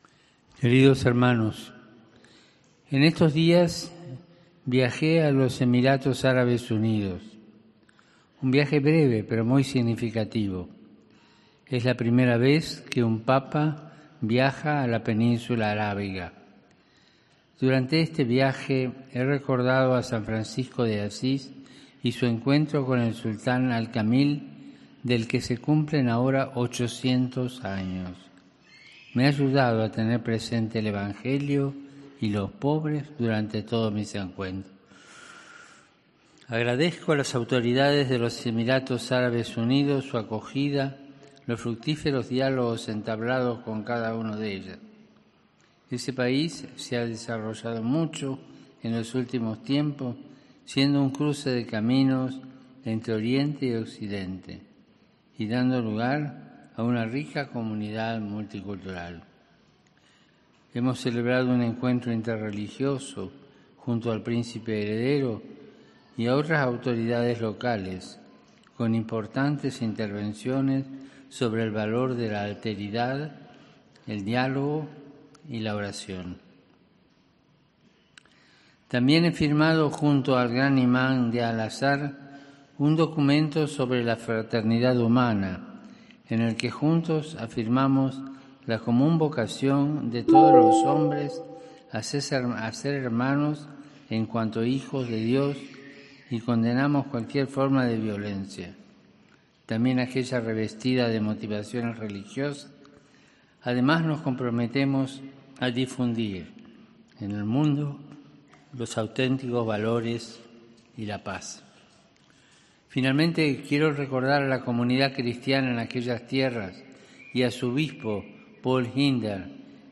Al final de su resumen en español, ha saludado a los peregrinos de habla hispana, y el público se ha roto en aplausos.
A lo que los andaluces presentes, han vuelto a responder con un fuerte aplauso.